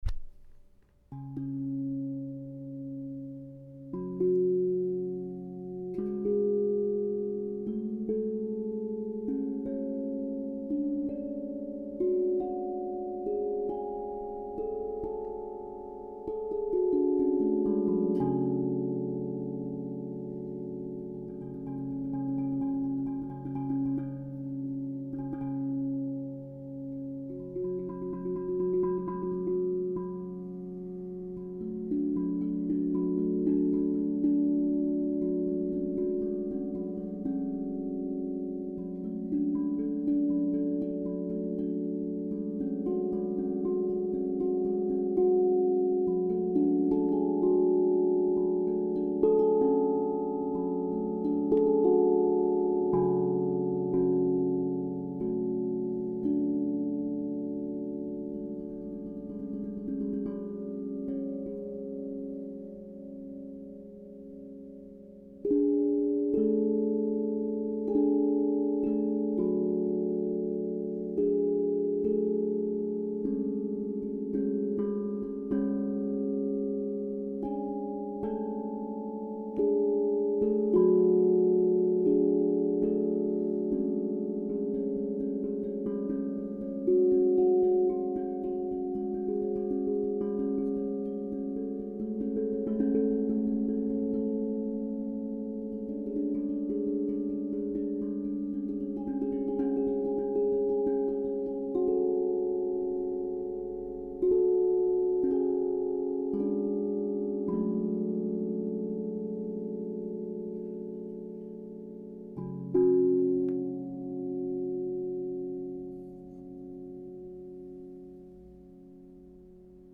Féminin sacré DO# Akebono 432 hz - Osb Drum
C'est une gamme Japonaise qui m'inspire la mélancolie, un lien profond et puissant avec certains moments de nos vies, une douceur dans l'ombre. Elle crée un paysage sonore particulier qui est à la fois mélancolique et apaisant, nous guide vers l'introspection et la contemplation.